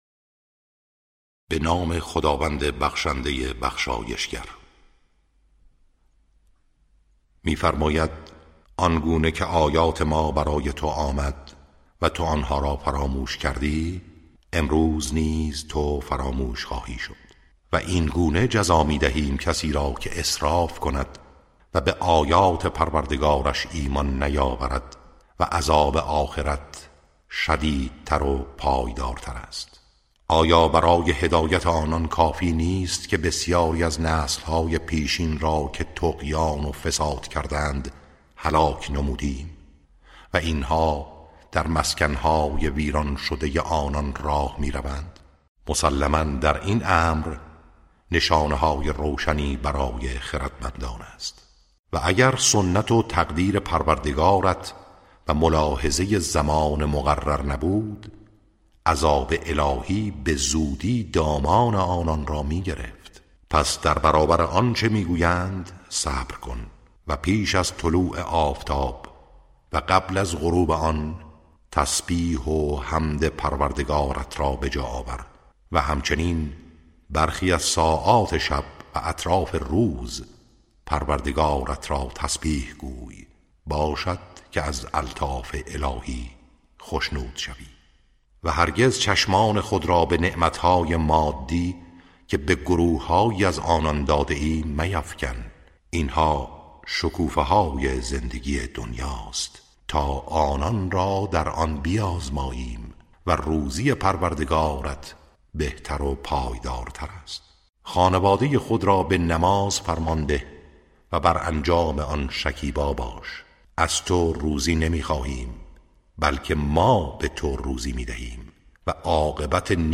ترتیل صفحه ۳۲۱ از سوره طه(جزء شانزدهم)